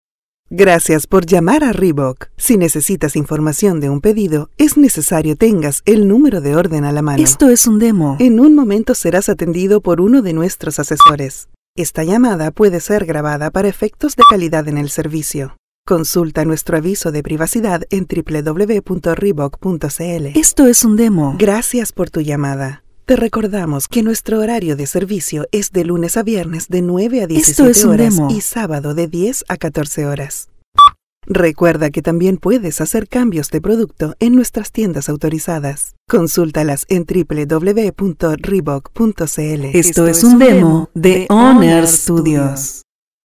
IVR
I have a versatile and clear Voice in Universal Neutral Spanish.
Young adult or adult female voice with a perfect diction, believable.
I own a professional audio recording studio, with soundproof booth included.